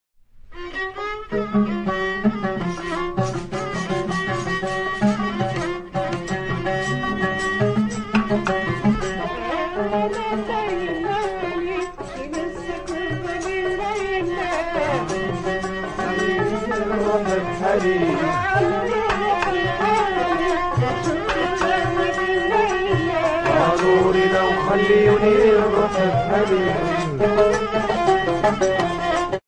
RABAB | Soinuenea Herri Musikaren Txokoa
Metalezko 2 soka ditu.
Igurtzitzeko uztaia du.
Rabab jotzen.
Instruments de musique: RABAB Classification: Cordes -> Frottées Emplacement: Erakusketa biltegia; kordofonoak; puntzatuak Explication de l'acquisition: 1995ean Hernaniko Axel artegintza dendan erosia.